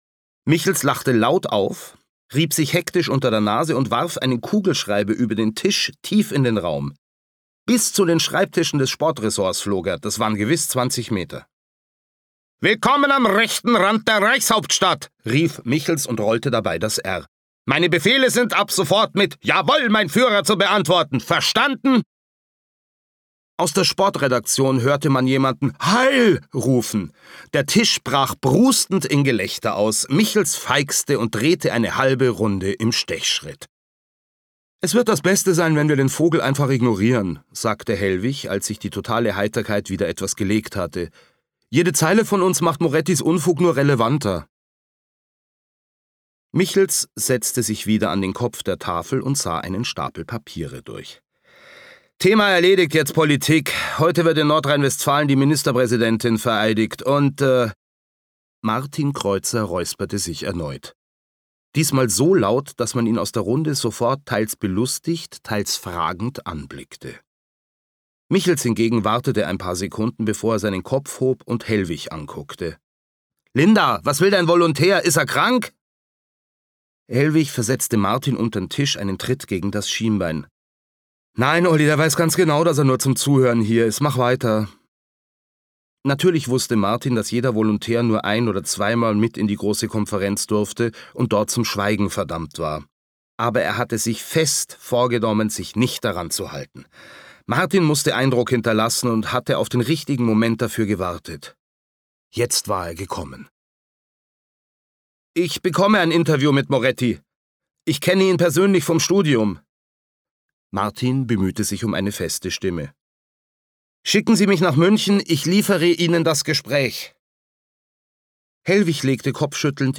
Mitarbeit Sprecher: Sebastian Bezzel